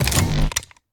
laser-turret-activate-01.ogg